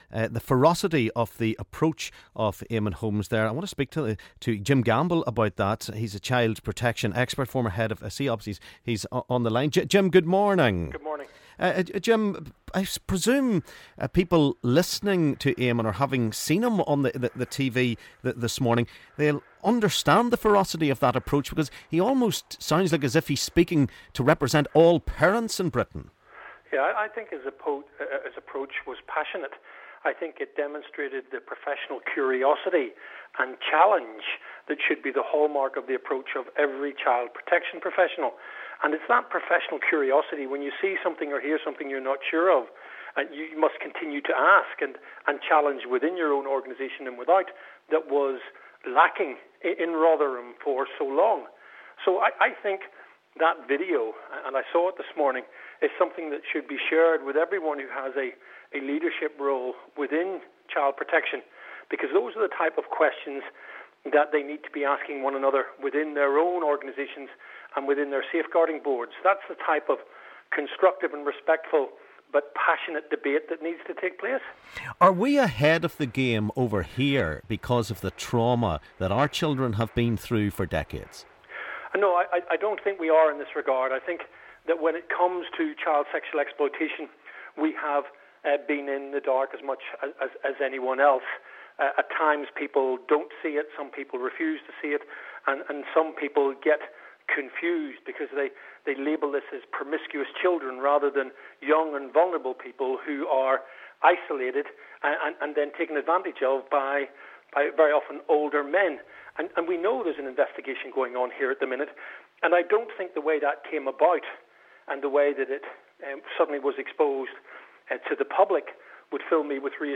Phone-In : Jim Gamble re. child exploitation in Rotherham